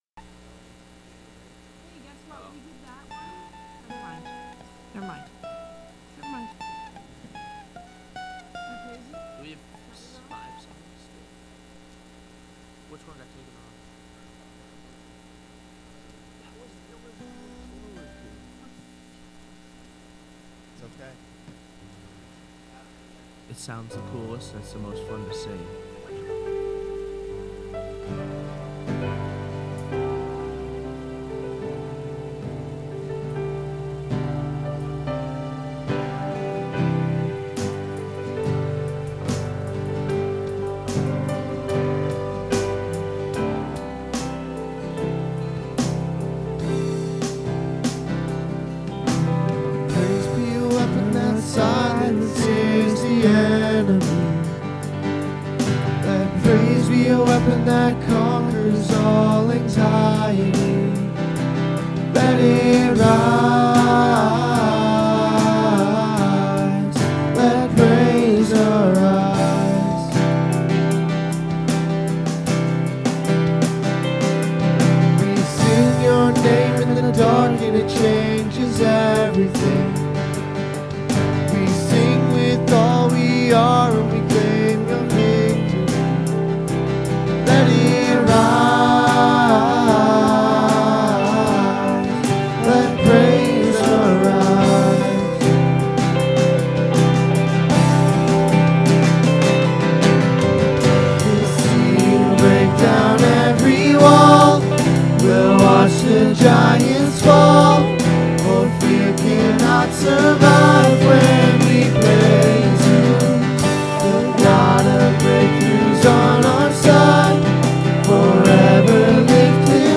Sunday Worship: 7-13-25